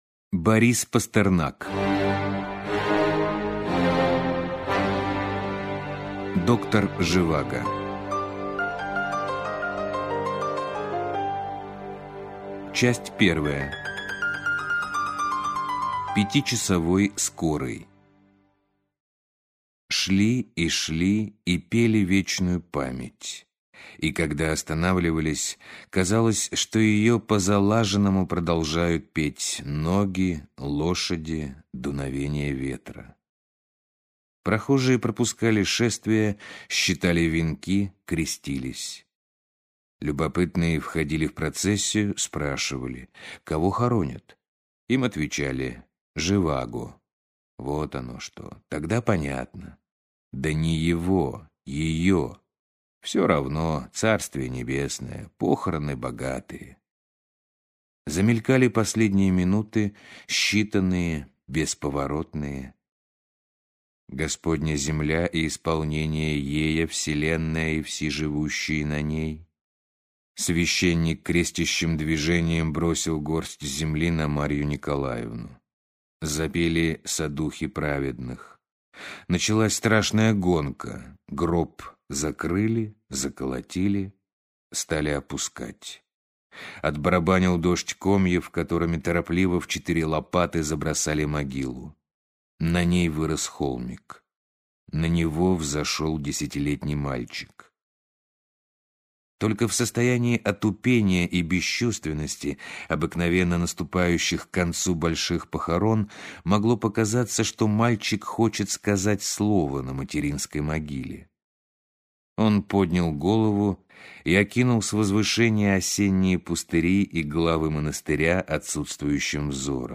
Аудиокнига Доктор Живаго - купить, скачать и слушать онлайн | КнигоПоиск